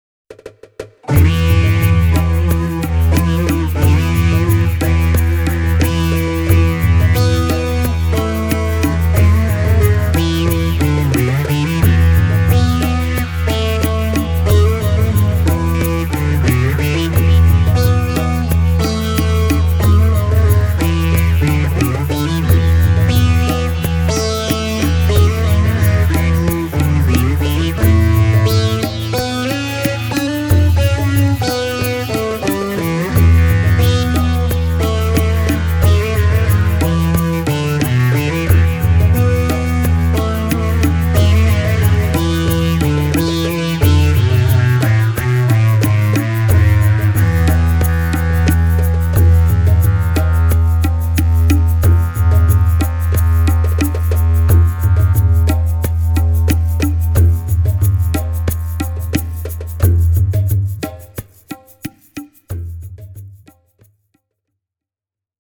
I chose to use the Boss synth to come up with a slightly Eastern-influenced patch, that uses two oscillators in series to produce a sitar-style twangy tone, with the third oscillator assigned to produce shimmering overtones.
The resulting patch sounds like this (a single guitar synth track, plus three tracks of Roland HandSonic-percussion):